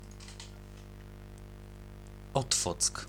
Otwock [ˈɔtfɔt͡sk]
Pl-Otwock.ogg.mp3